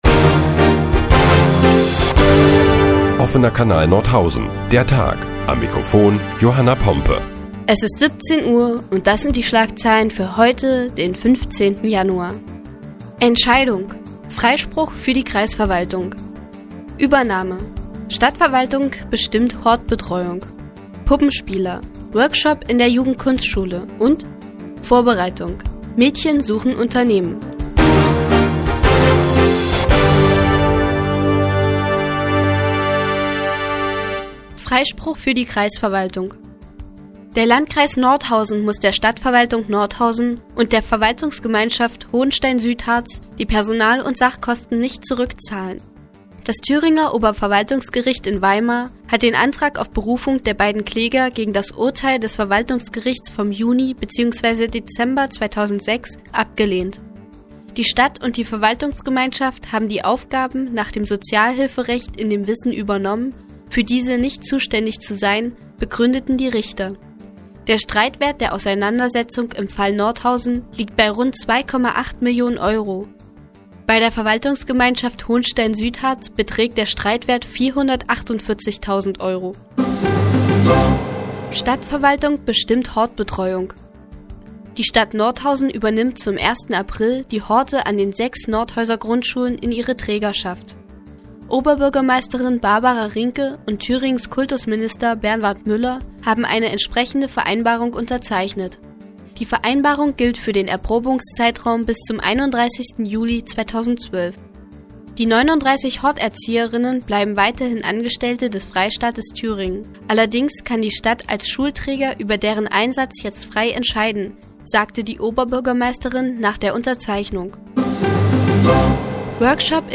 Die tägliche Nachrichtensendung des OKN ist nun auch in der nnz zu hören. Heute geht es unter anderem um die Entscheidung des Oberverwaltungsgerichts und um die zukünftige Leitung der Nordhäuser Horte.